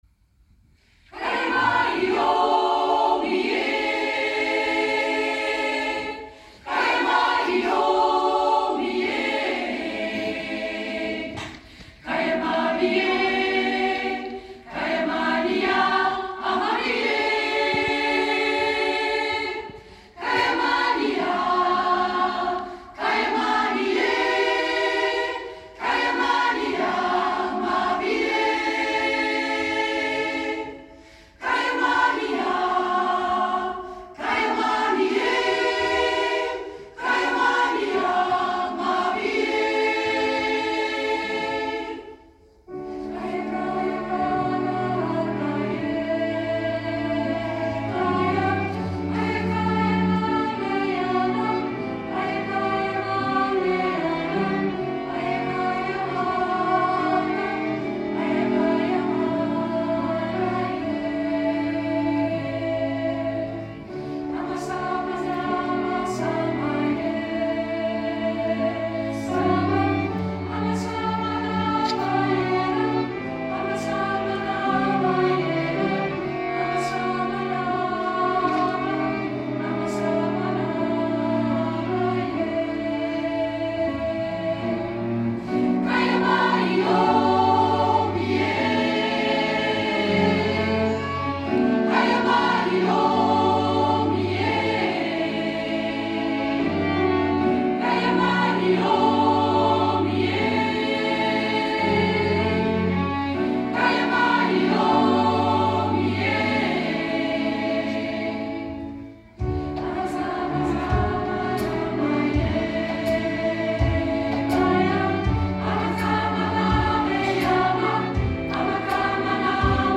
Najaarsconcert 22 september 2024